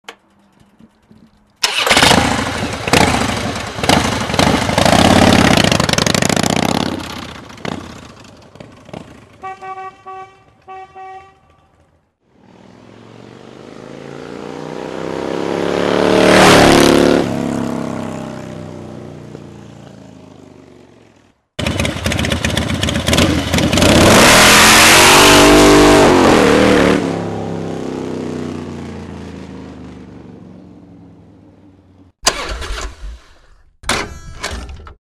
Рок-попс сегодня свёл.
Сделайте зацен плиз,клавишь тут нет,гитары разные всякие,еффект на соло партии делал саплитудовским Filtox-ом что то типа авто- вау.
Гитара Gibson самая запростетская из серии студио,а акустика Ibanez самый кетай.:good: А тома в этих брейках "быстрые" и мазать их не стал по этому. Да барабаны тоже не ахти какие-это ямаха серии YD тоже самый кетай,просто отстроил её хорошо.Рабочий марех железный 3.5 дюймовый.